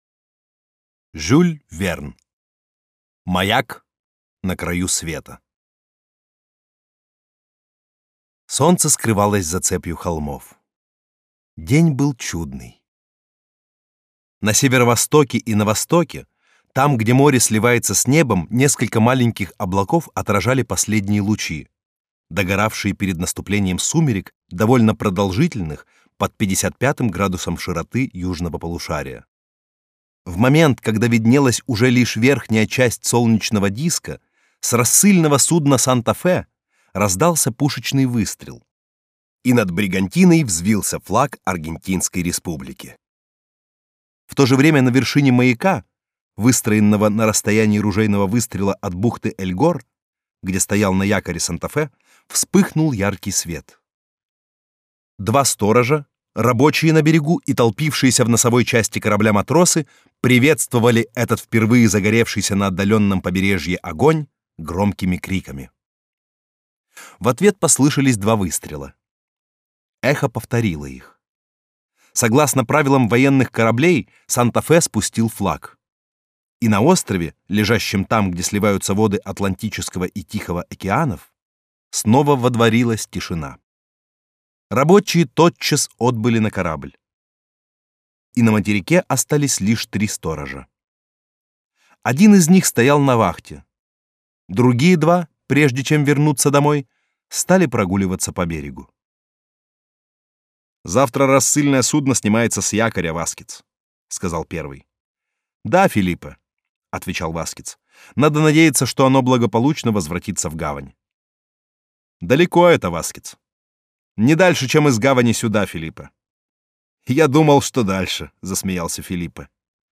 Аудиокнига Маяк на краю света | Библиотека аудиокниг